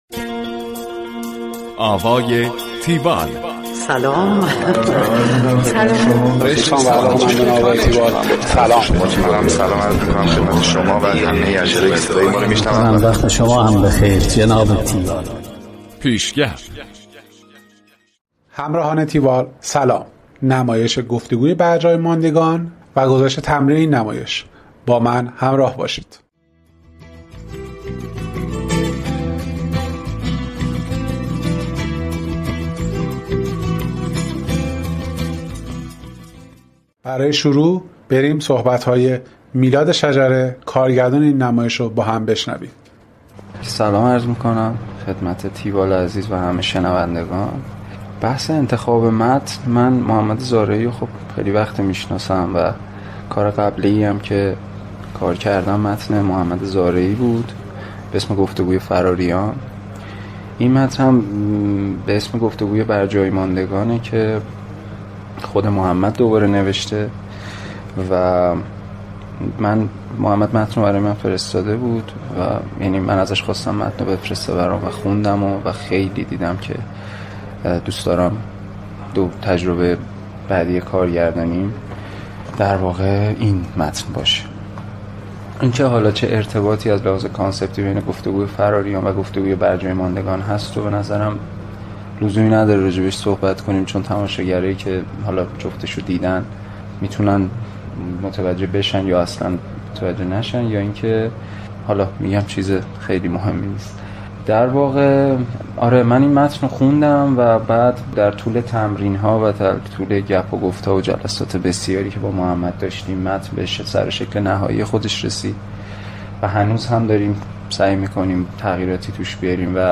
گزارش آوای تیوال از نمایش گفت و گوی برجای ماندگان